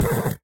Sound / Minecraft / mob / horse / hit4.ogg
hit4.ogg